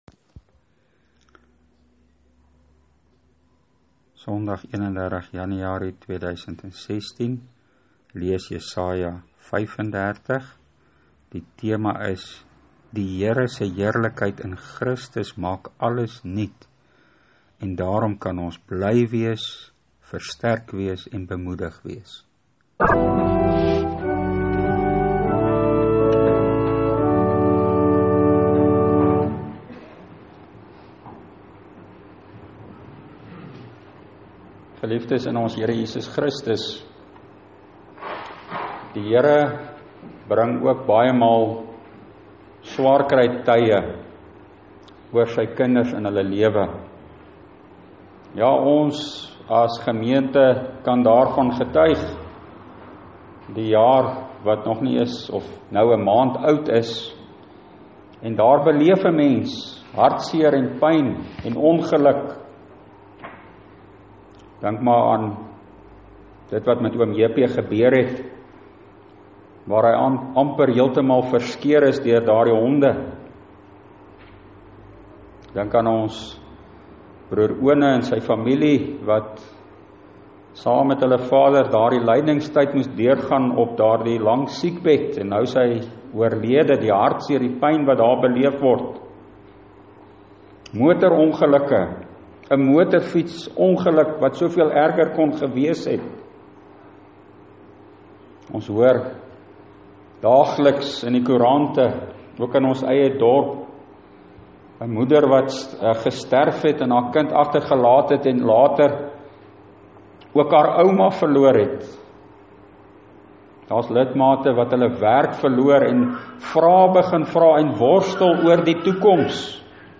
Preek: Jesaja 35 – Jesus Christus maak alles nuut !